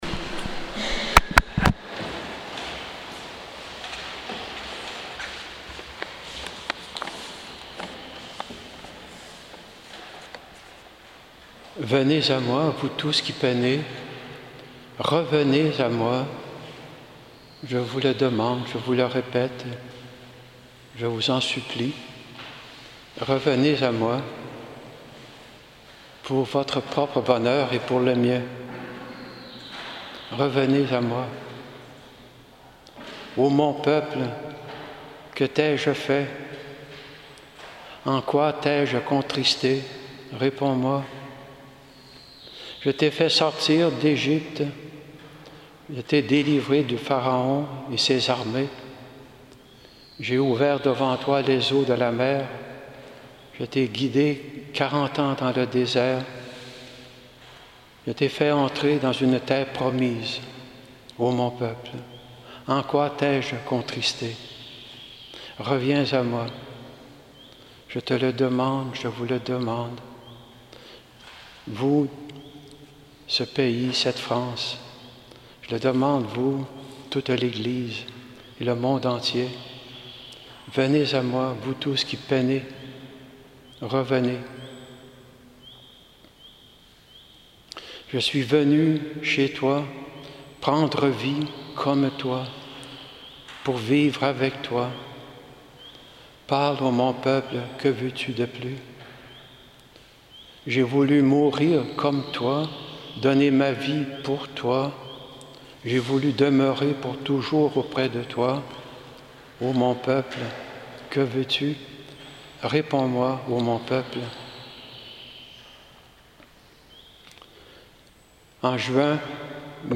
Homélie Solennité du Coeur de Jésus